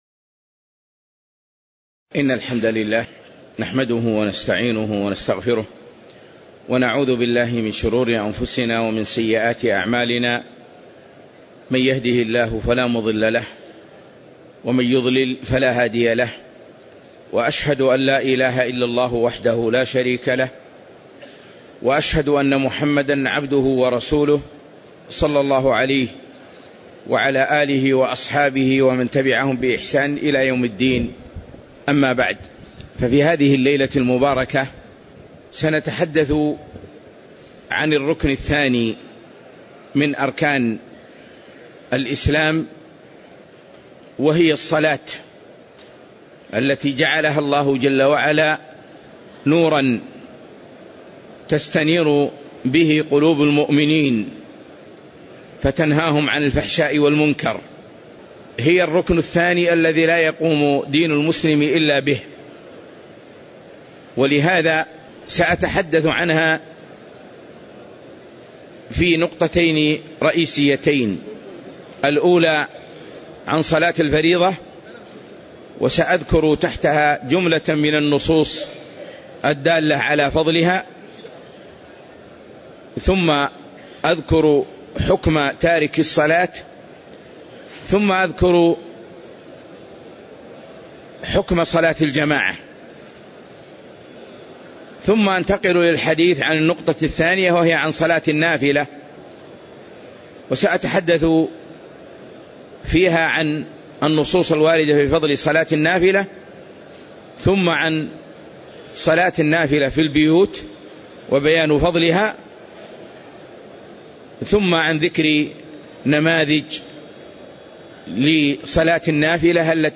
محاضرة - الصلاة نور